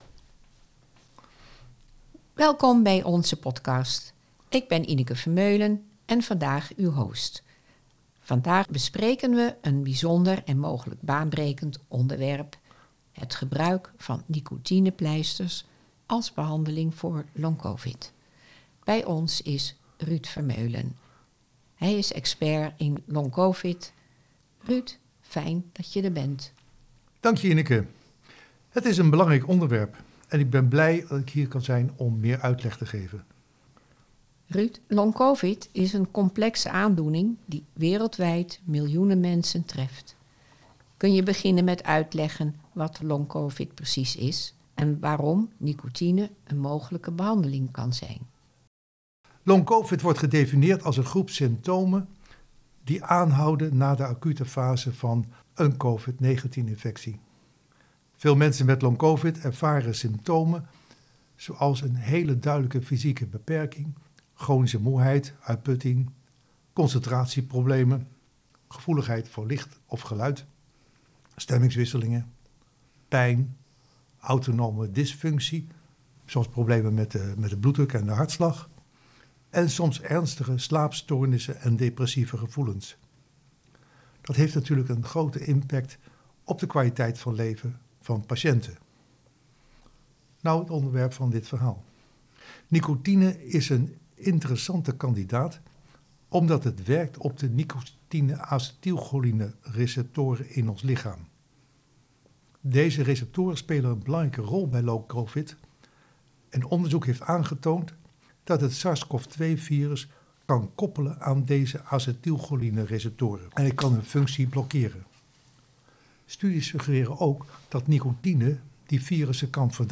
Host:
Expert: